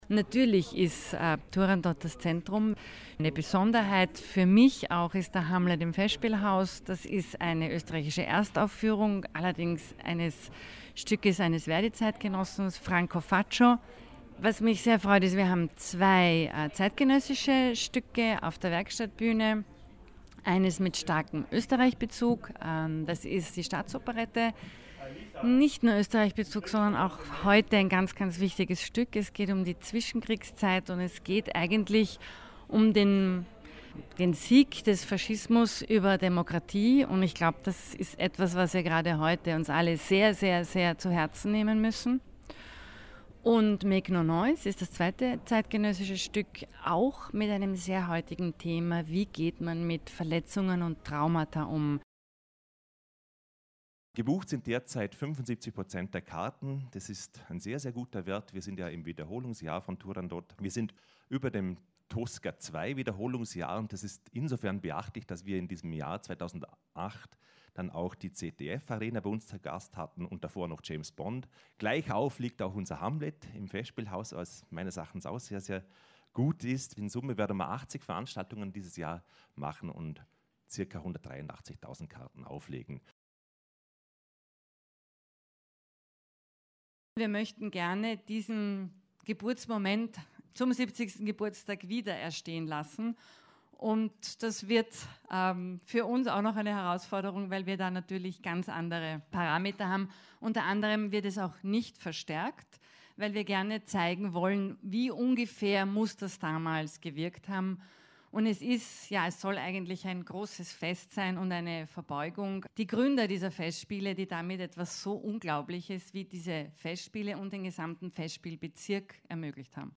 Pressetag 2016 Audio O-Töne
bregenz_pressetag2016-feature.mp3